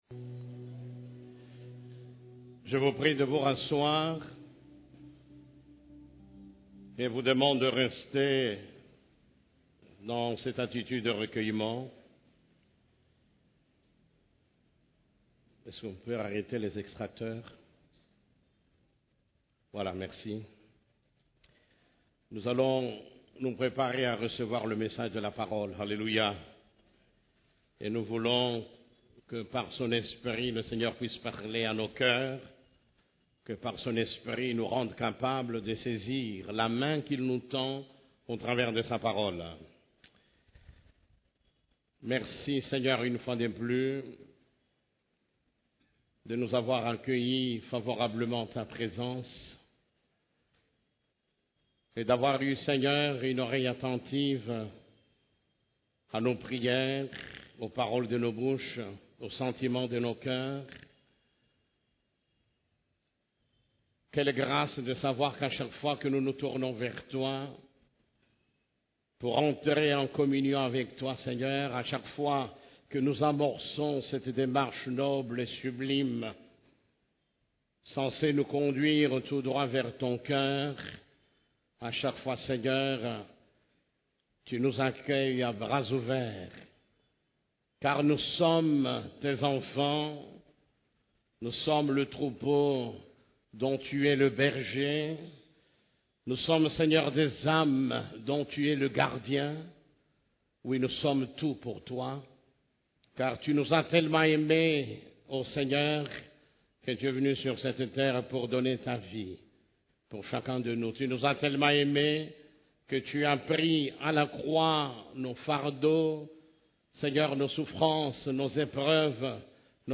CEF la Borne, Culte du Dimanche, Le lieu secret où il fait bon d'être 2